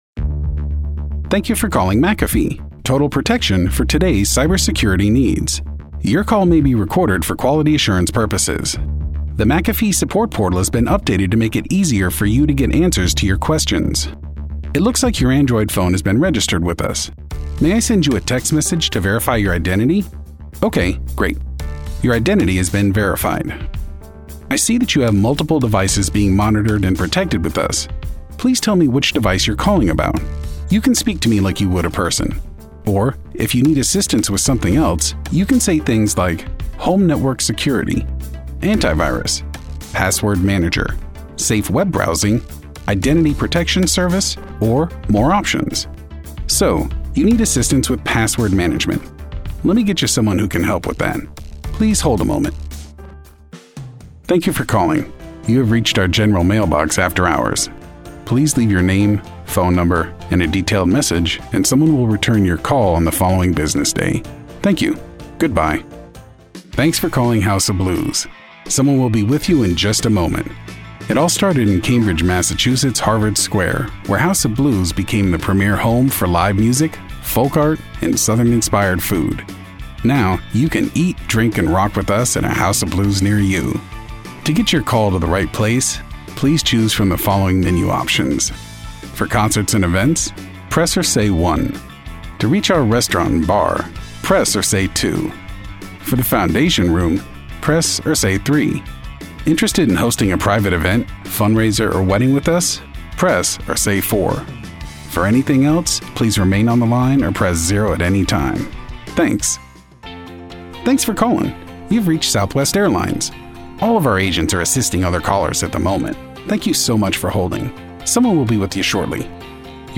IVR / Telephony Demo
English - USA and Canada
Young Adult
Middle Aged